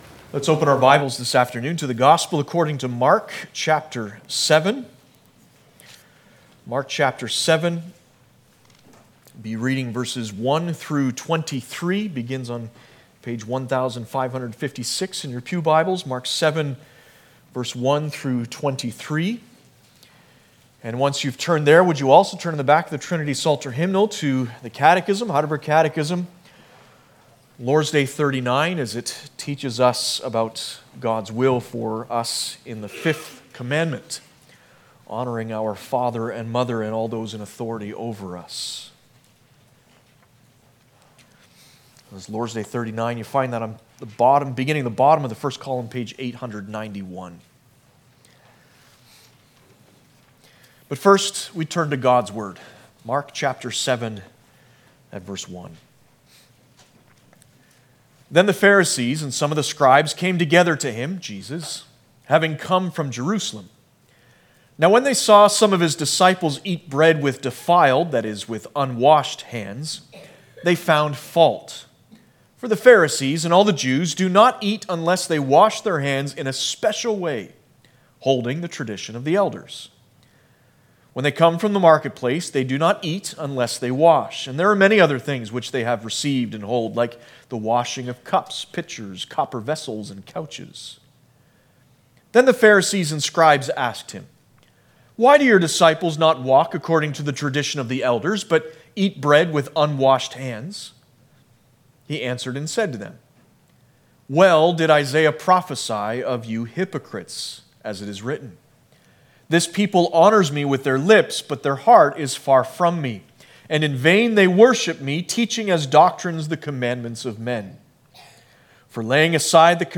Passage: Mark 7:1-23, Lord's Day 39 Service Type: Sunday Afternoon